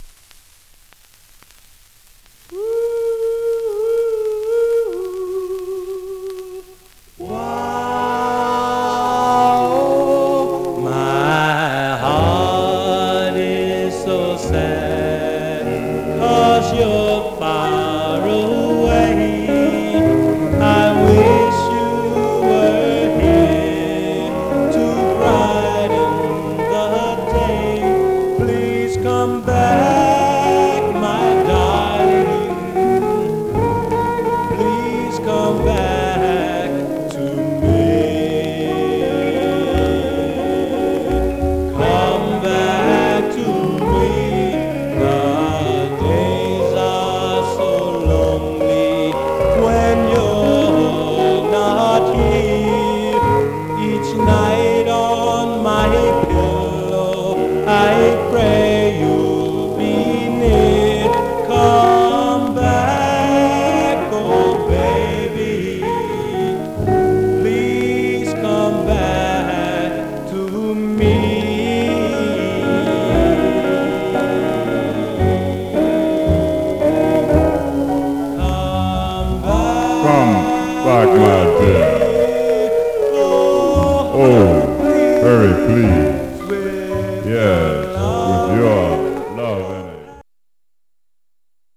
Surface noise/wear Stereo/mono Mono
Male Black Group